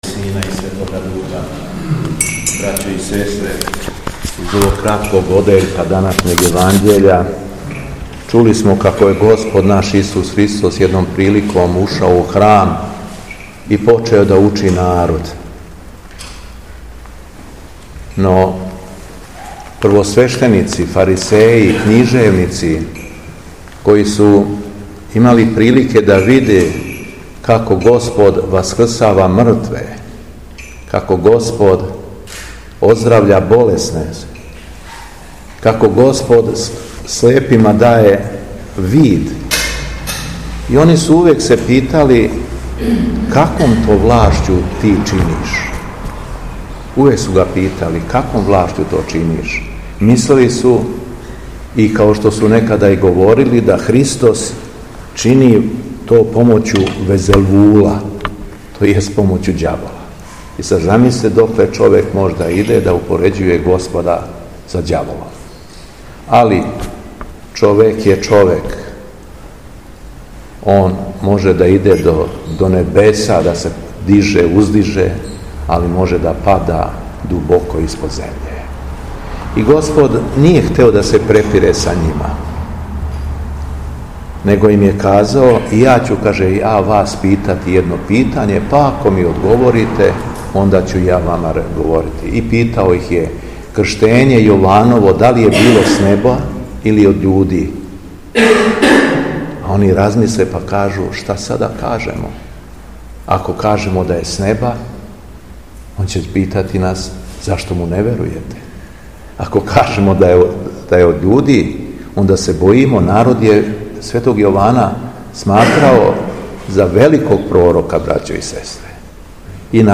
У уторак, 27. августа 2024. године, Његово Високопреосвештенство Митрополит шумадијски Господин Јован служио је Свету Архијерејску литургију у храму Светог великомученика Пантелејмона у Станову.
Беседа Његовог Високопреосвештенства Митрополита шумадијског г. Јована
Након прочитаног јеванђелског зачала, Епископ се беседом обратио вернима, рекавши: